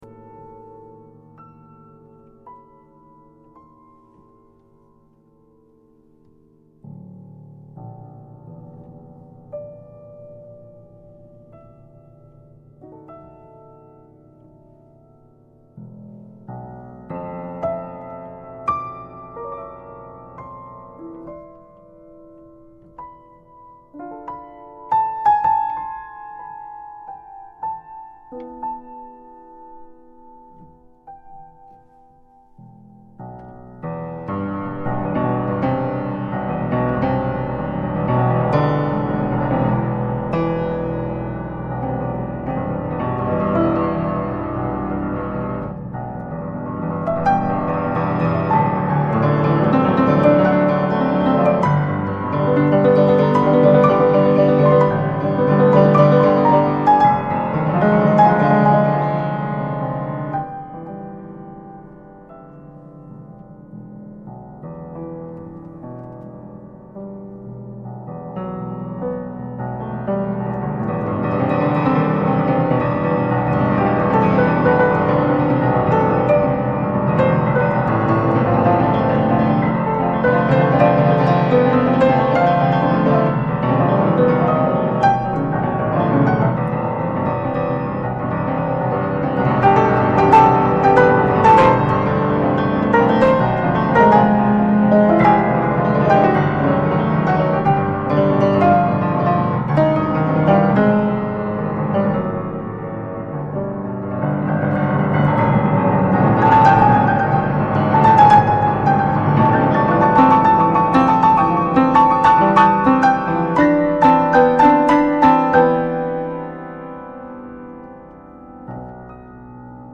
- Все, что вы услышите здесь, друзья, создано и исполнено одновременно.
На обычном языке это именуется импровизацией.
Почти все пьесы сыграны на пианино, на старом моем Беккере.
Прошу вашего снисхождения к качеству домашней аудиозаписи и техническим погрешностям моего исполнения, которые я намеренно не правлю аудиоредактурой, чтобы сохранить живой, непосредственный характер сего действа.